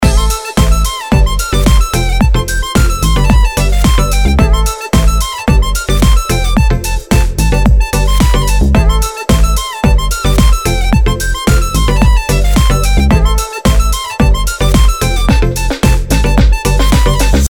И в миксе с дудкой из EW